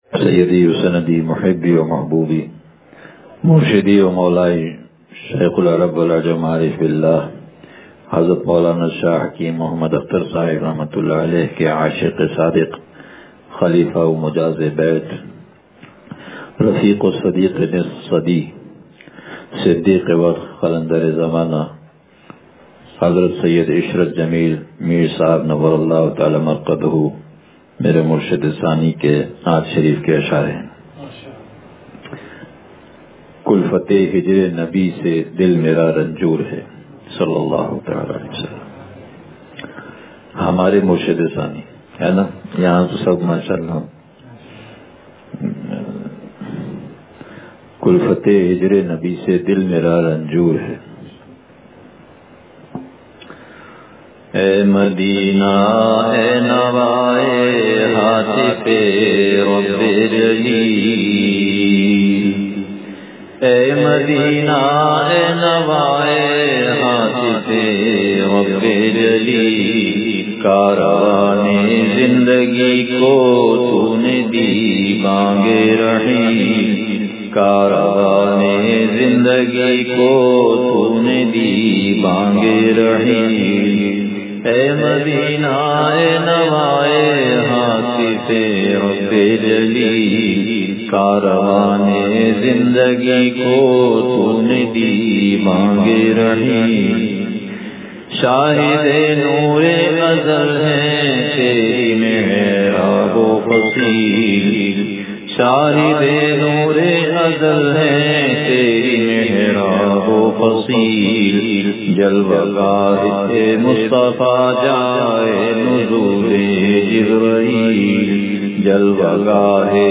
کلفتِ ہجرِ نبی صلی اللہ علیہ وسلم سے دل مرا رنجور ہے – مجلس بروز جمعرات